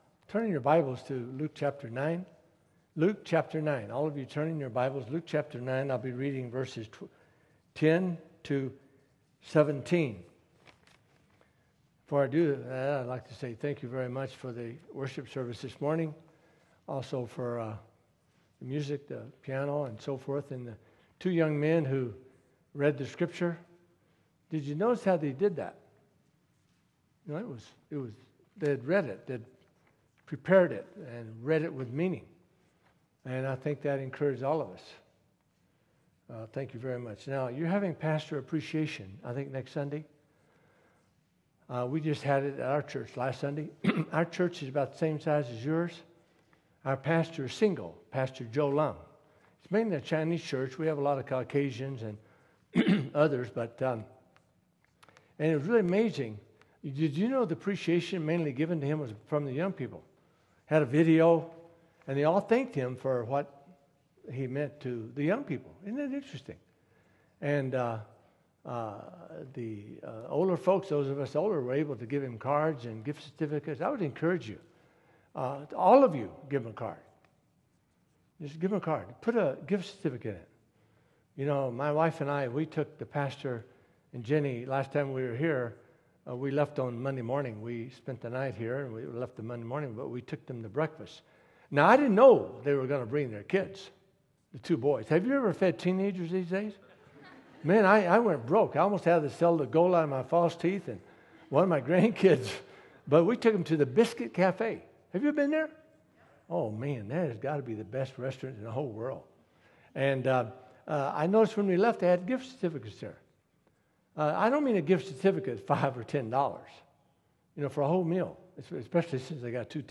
Passage: Luke 9:10-17 Service Type: Sunday Service « Guest Speaker